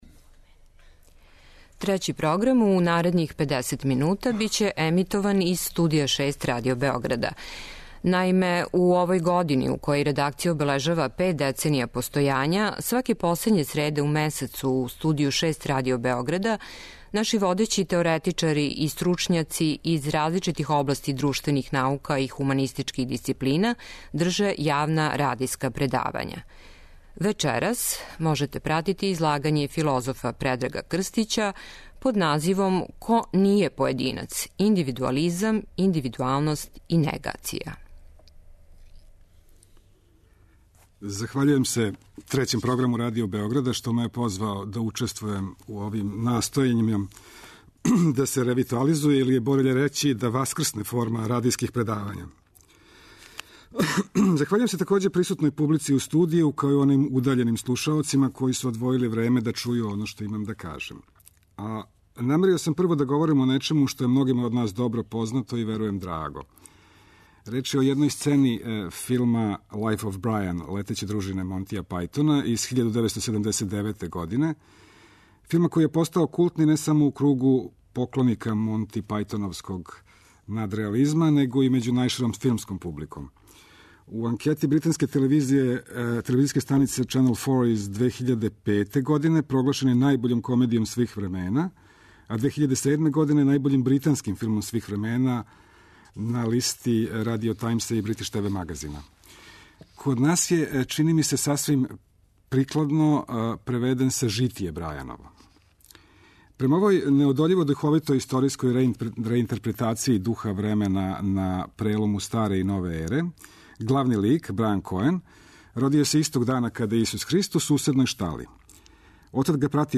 Радијско предавање Трећег програма
преузми : 23.13 MB Радијска предавања, Дијалози Autor: Трећи програм Из Студија 6 директно преносимо јавна радијска предавања.